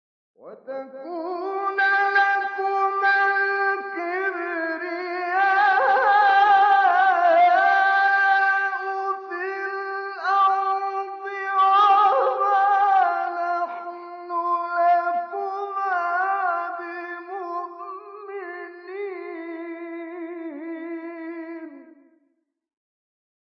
گروه شبکه اجتماعی: فرازهای صوتی اجرا شده در مقام حجاز با صوت کامل یوسف البهتیمی ارائه می‌شود.
برچسب ها: خبرگزاری قرآن ، ایکنا ، شبکه اجتماعی ، مقاطع صوتی ، مقام حجاز ، کامل یوسف البهتیمی ، قاری مصری ، تلاوت قرآن ، قرآن ، iqna